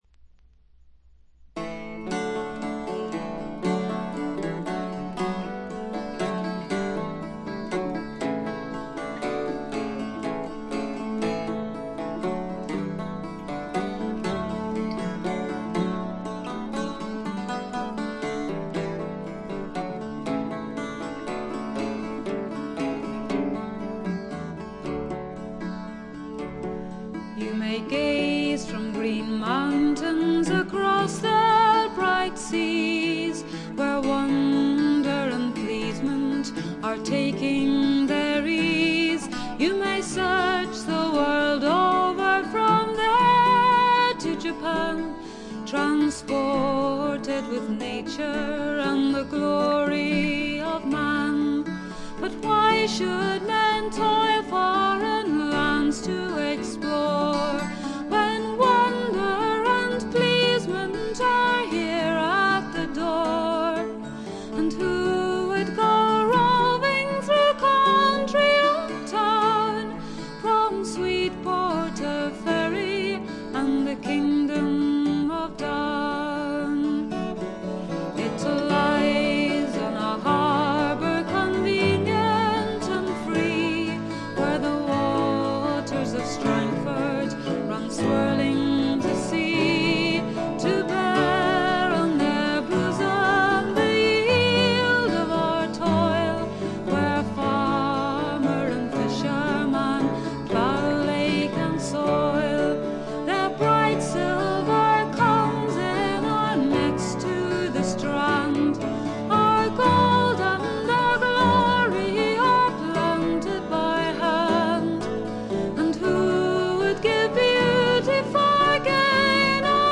ほとんどノイズ感無し。
アイルランドの女性シンガー
天性のとても美しい声の持ち主であるとともに、歌唱力がまた素晴らしいので、神々しいまでの世界を構築しています。
試聴曲は現品からの取り込み音源です。
Bagpipes [Uilleann Pipes]
Vocals, Bouzouki, Acoustic Guitar